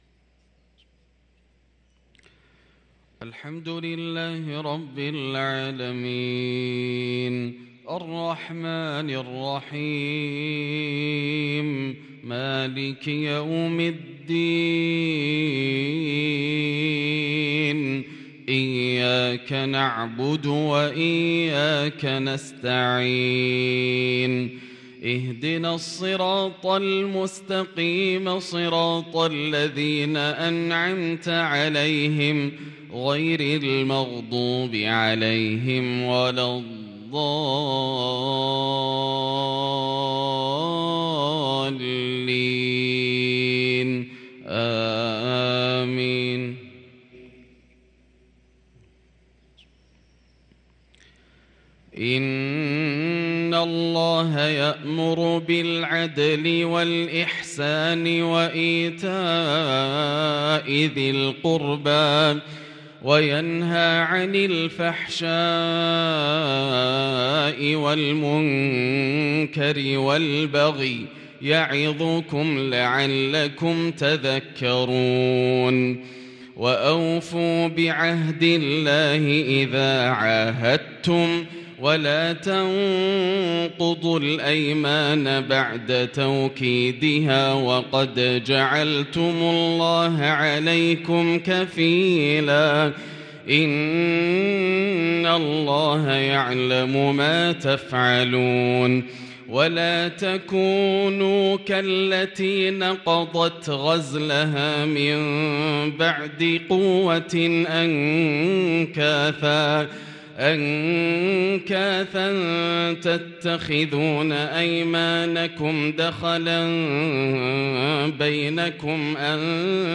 صلاة الفجر للقارئ ياسر الدوسري 27 ربيع الآخر 1444 هـ
تِلَاوَات الْحَرَمَيْن .